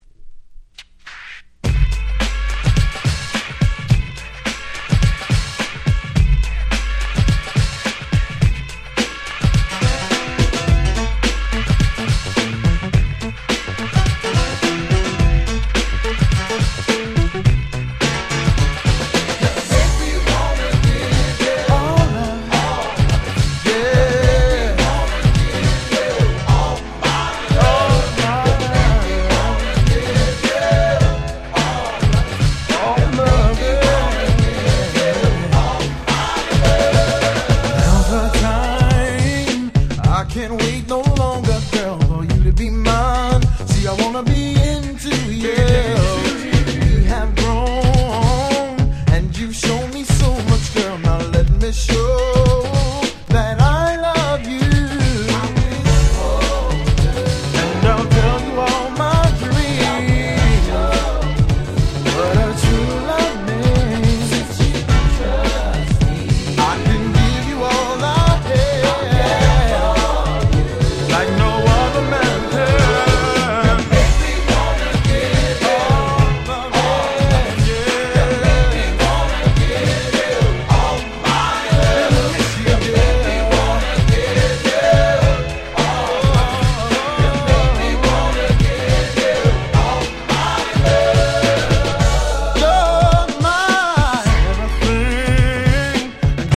93' Very Nice New Jack Swing / R&B !!
ハネたBeatにキャッチーなMelodyが100点満点！！
元気いっぱい、ハッピーな好曲です♪
NJS ハネ系 キャッチー系 90's